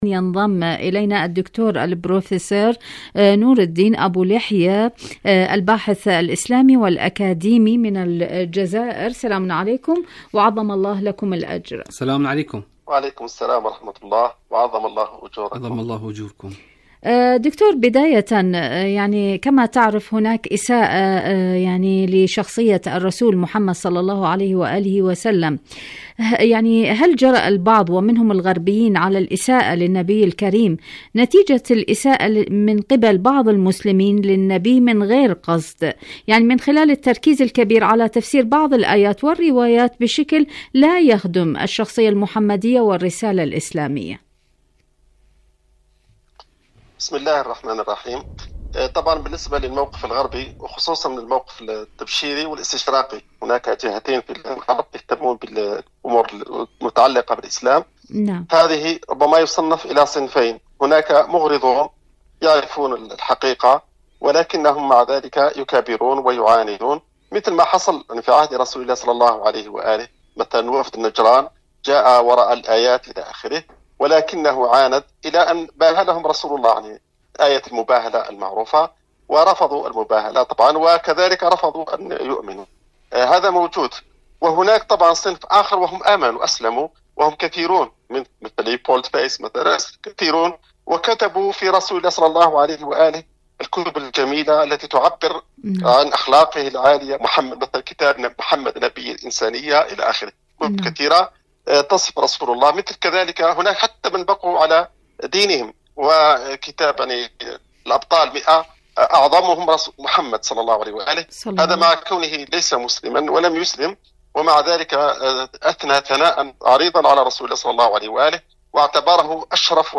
إذاعة طهران- رحيل خاتم النبيين: مقابلة إذاعية